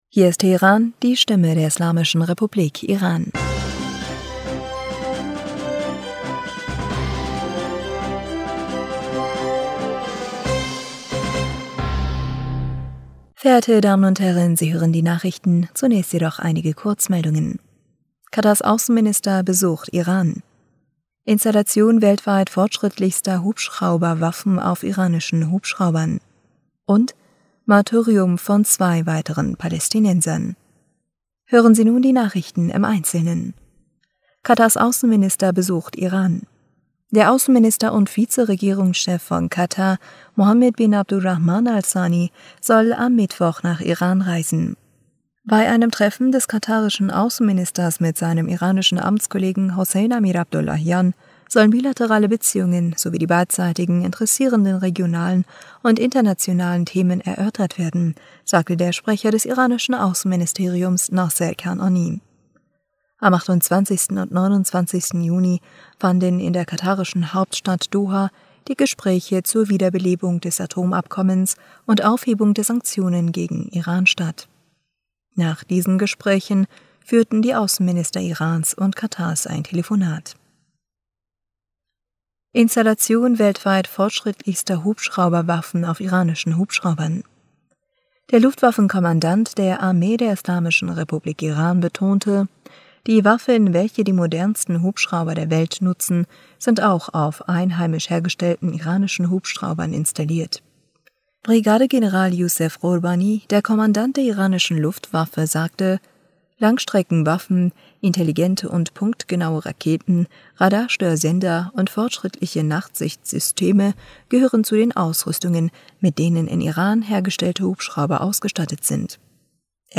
Nachrichten vom 6. Juli 2022